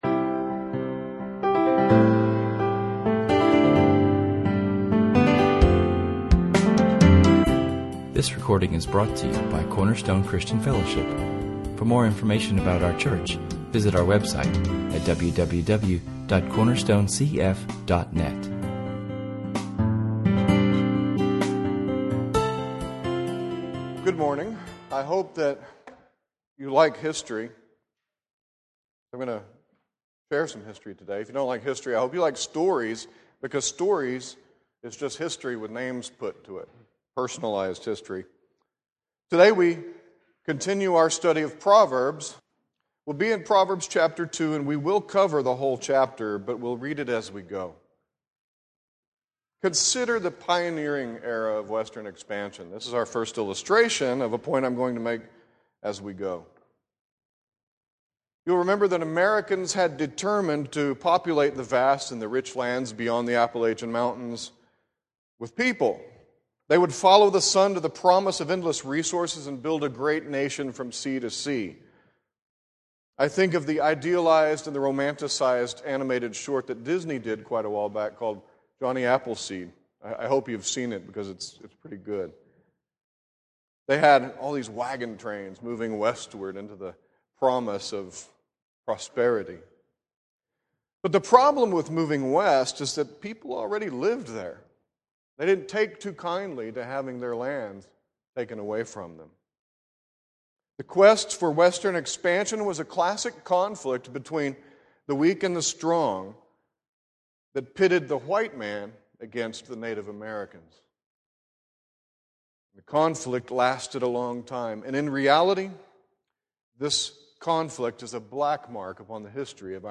In this sermon filled with illustrations from history and daily life, we learn that where there is conflict, men need a guide and a guardian to see them through.